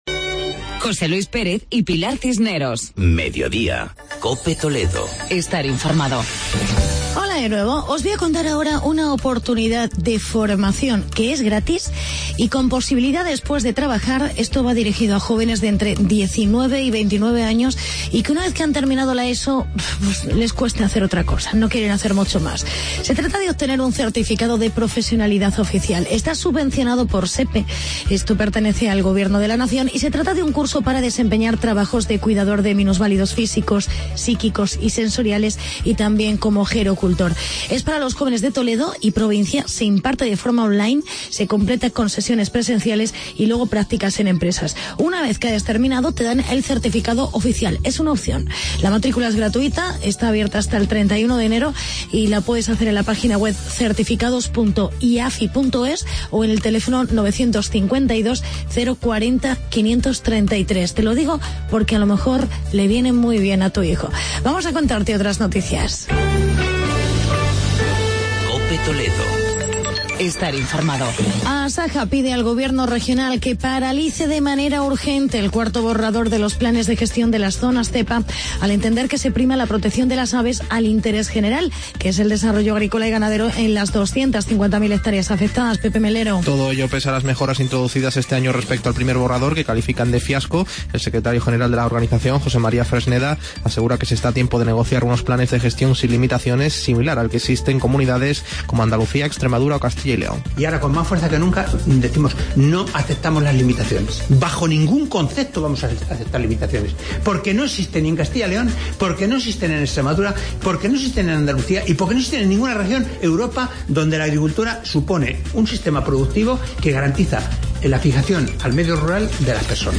Actualidad y entrevista con el concejal de turismo, José Luis Muelas.